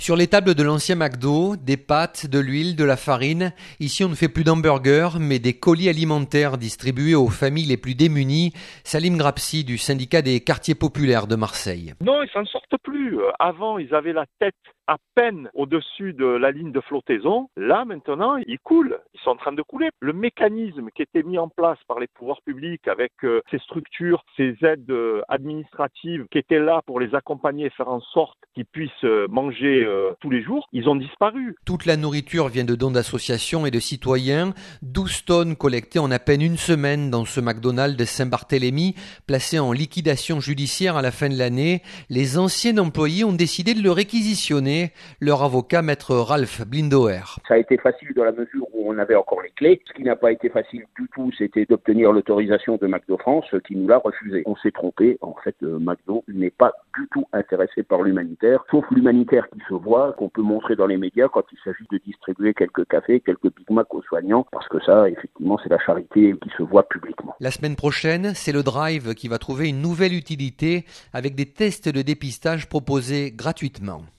enquête